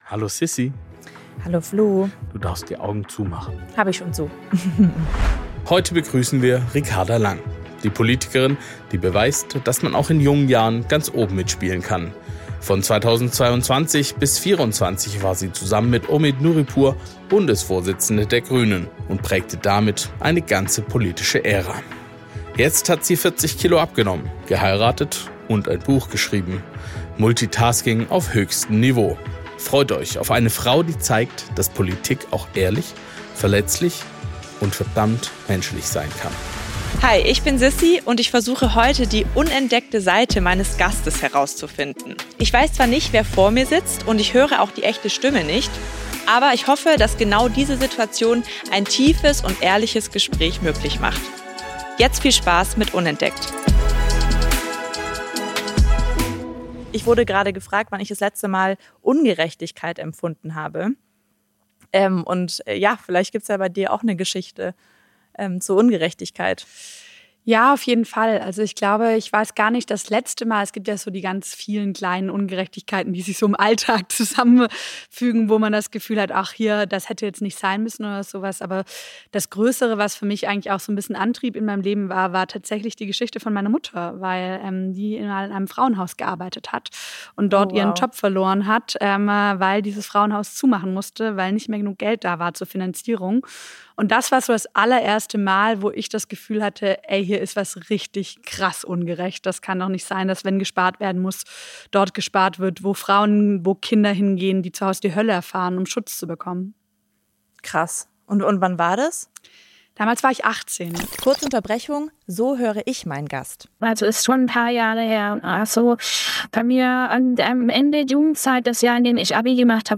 Deep Talk